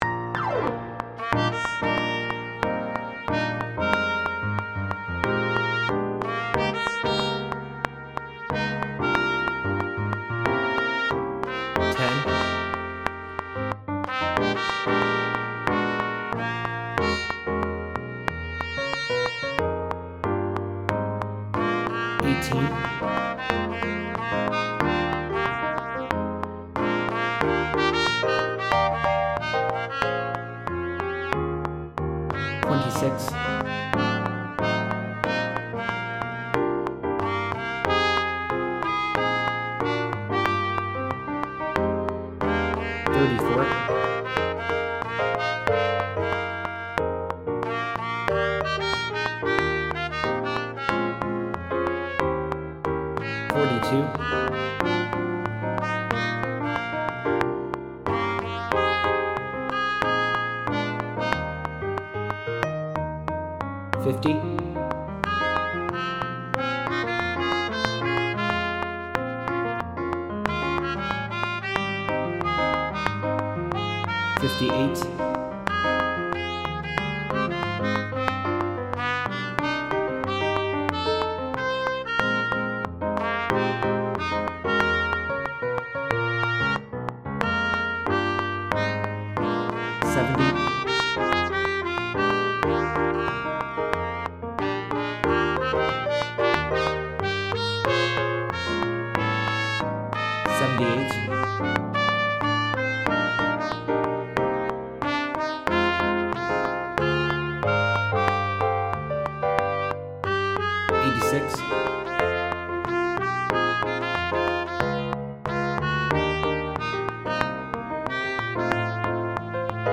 Sop 1, 2 balanced